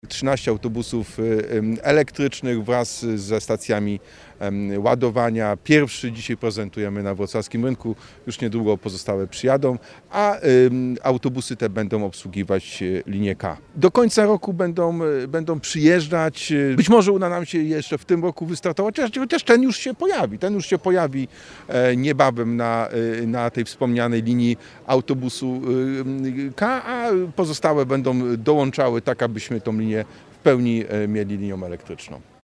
– Wrocławianie znają dobrze autobusy typu Mercedesów e-Citaro G – mówi Jacek Sutryk, prezydent Wrocław.